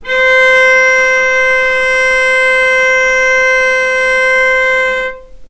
vc-C5-mf.AIF